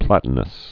(plătn-əs)